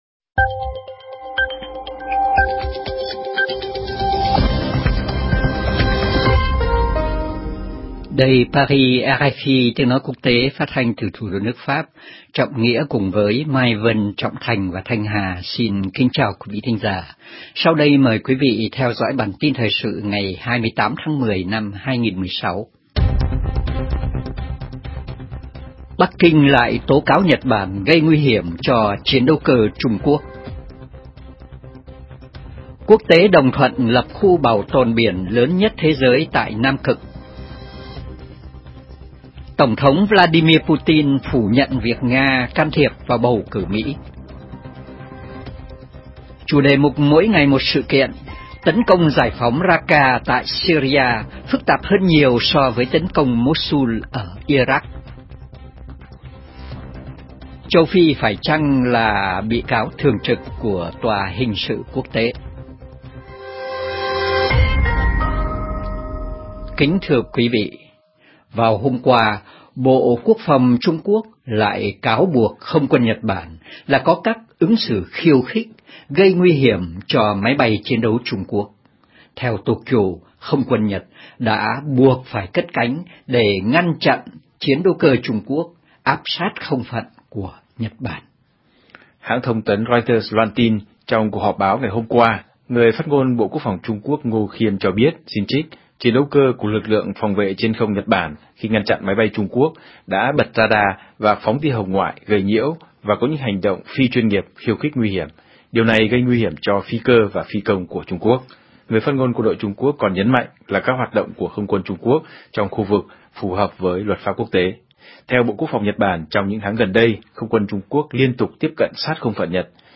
Chia sẻ lên X Chia sẻ lên Facebook Chia sẻ lên Pinterest MỤC : AUDIO - NGHE CHƯƠNG TRÌNH PHÁT THANH